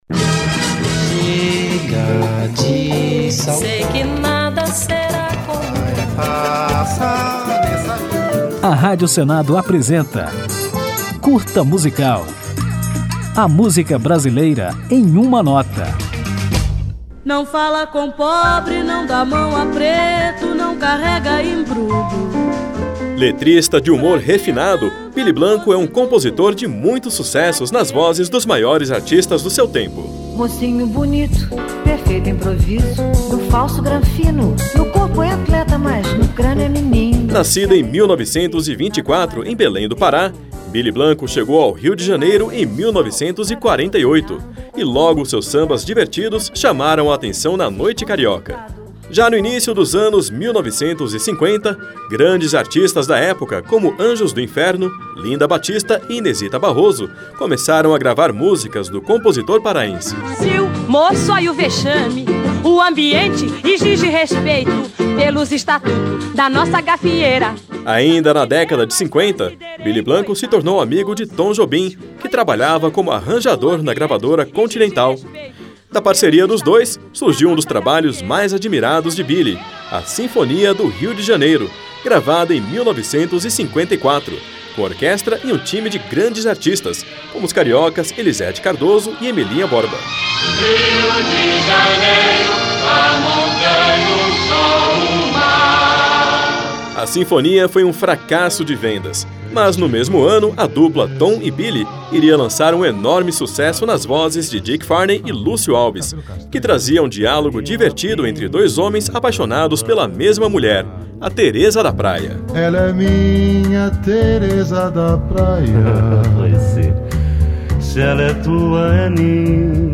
Com humor refinado, ele compôs muitos sucessos, como A Banca do Distinto, Sinfonia do Rio de Janeiro e Tereza da Praia, além de Piston de Gafieira, a música que ouviremos ao final do programa, na interpretação do próprio Billy Blanco